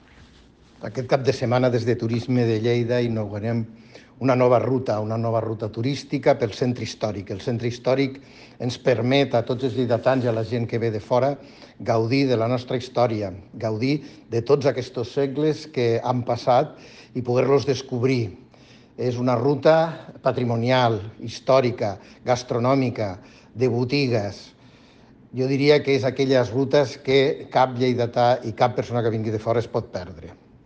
tall-de-veu-del-tinent-dalcalde-paco-cerda-sobre-la-nova-ruta-de-turisme-de-lleida-al-centre-historic